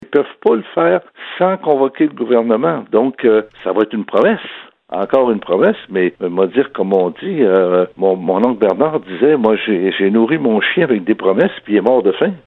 Lors de son entretien hebdomadaire au VIA 90,5 FM, dans l’émission Debout c’est l’heure, le député de Bécancour-Nicolet-Saurel rappelle qu’elles devaient arriver en juin et qu’elles sont maintenant attendus en août.